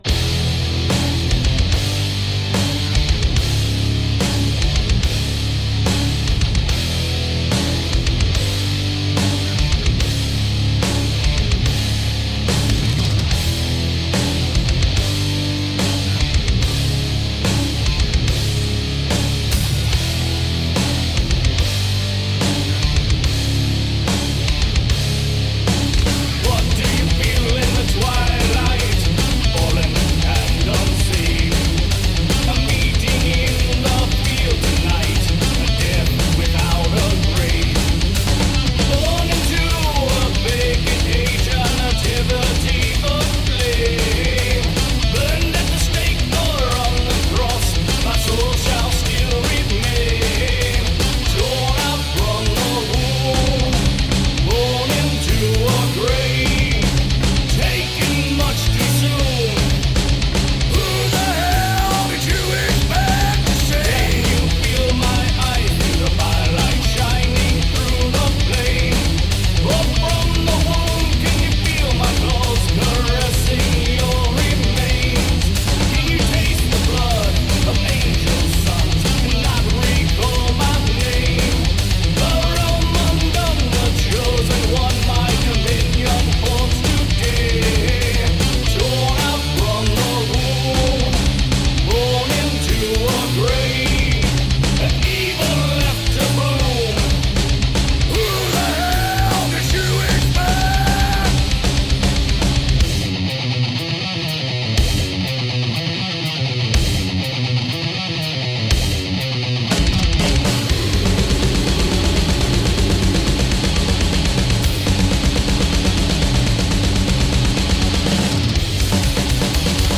melodic heavy rock band